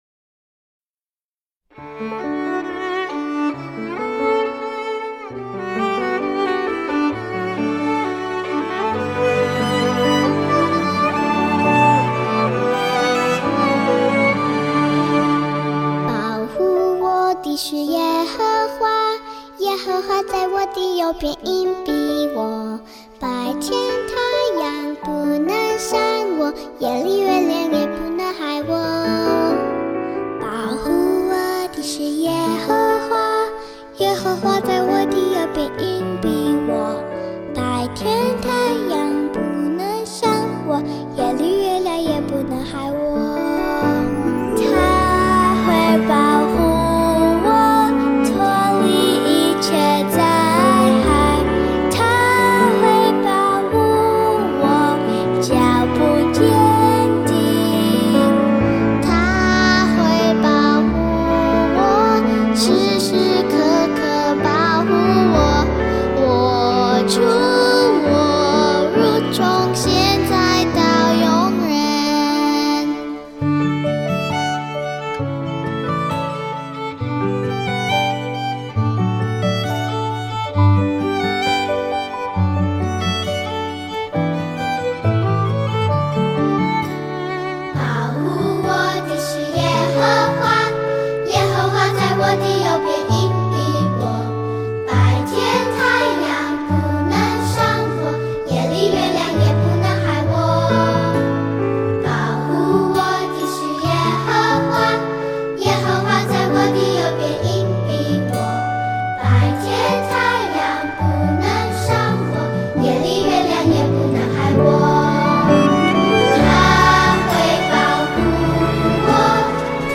视频里有动作演示，音频里歌会自动重复三遍。